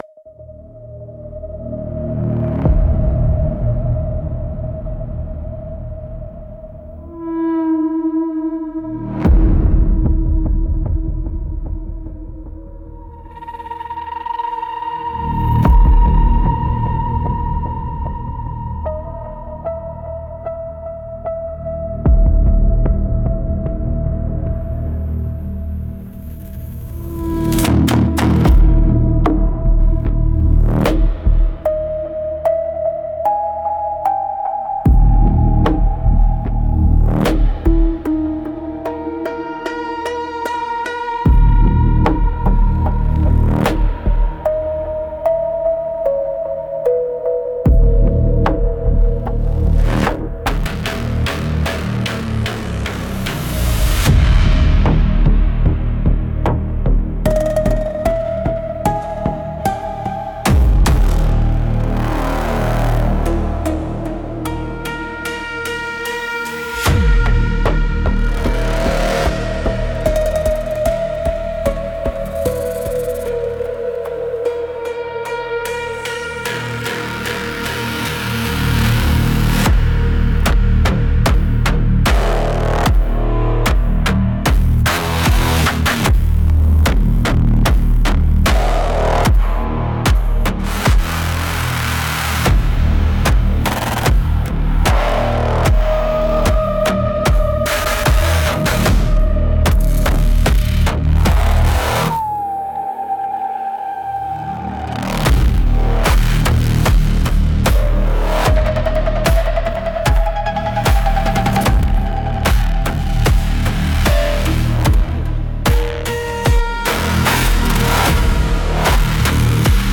Instrumentals - The Slow Crush of Progress